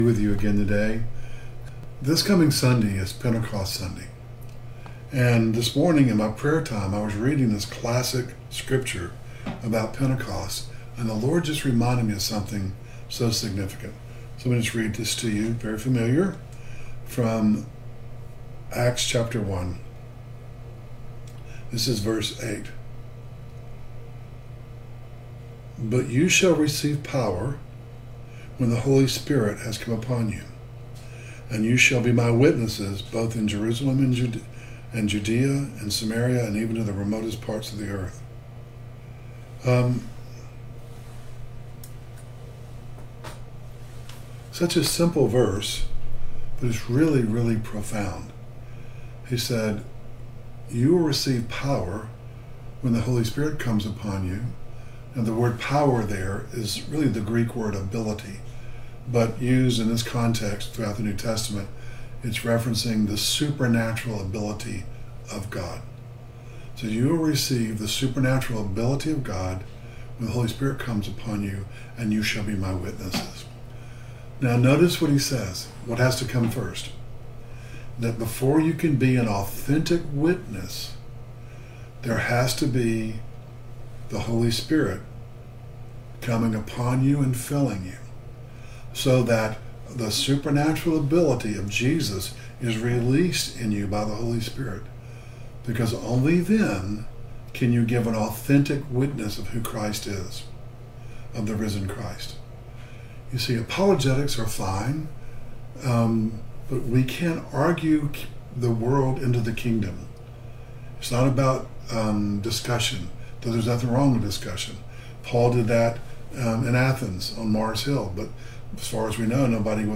Originally on Facebook Live 6/5/25